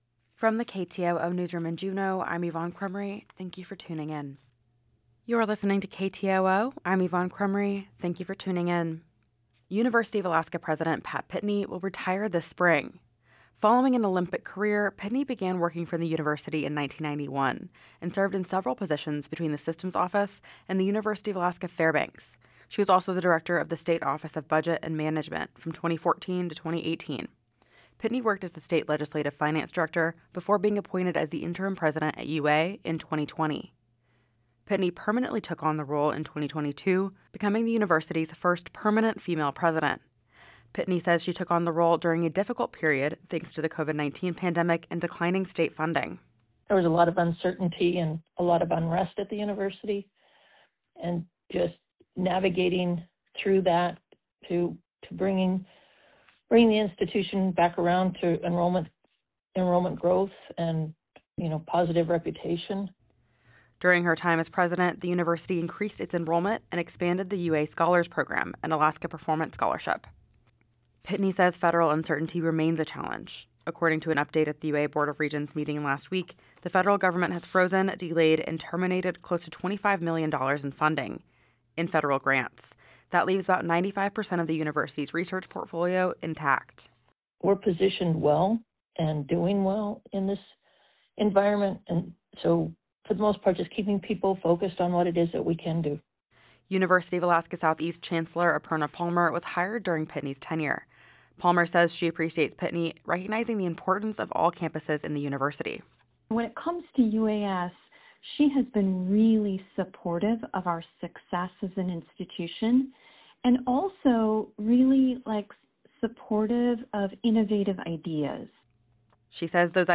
Newscast – Thursday, No.v. 13, 2025